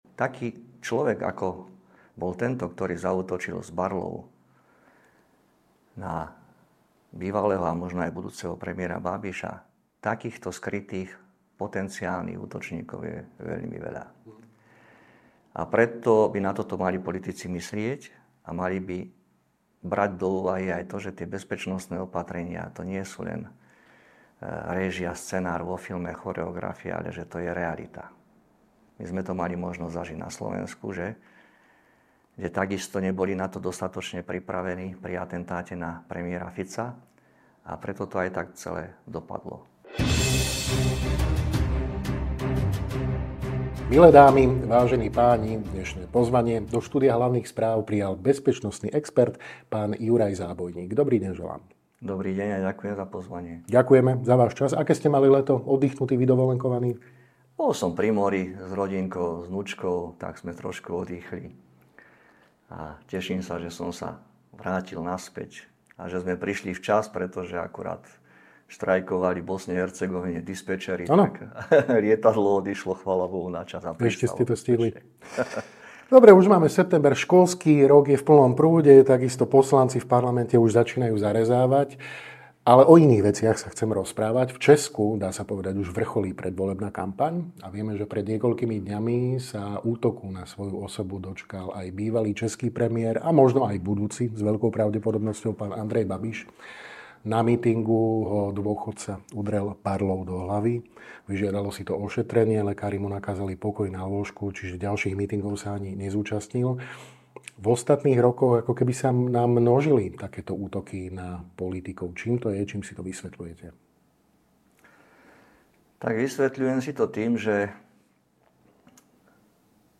V rozhovore pre Hlavné správy otvorene pomenoval riziká, ktoré dnes ohrozujú politikov a varuje pred podceňovaním prevencie.